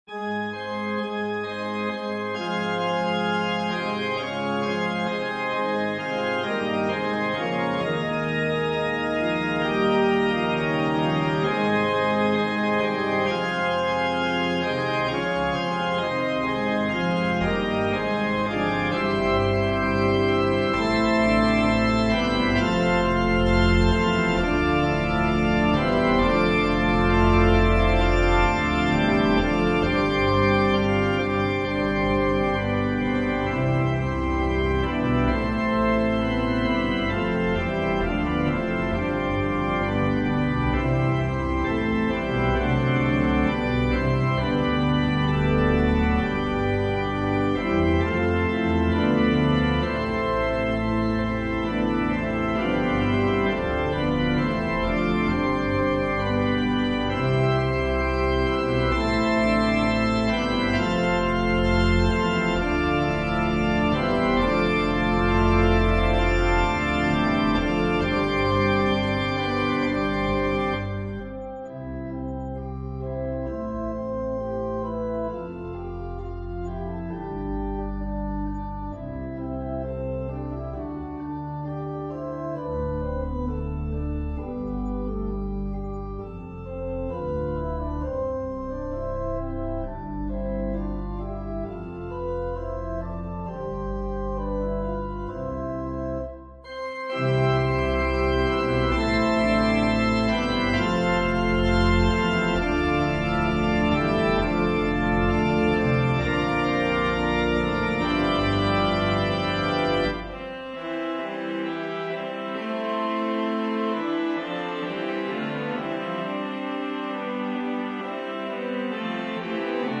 Voicing/Instrumentation: Organ/Organ Accompaniment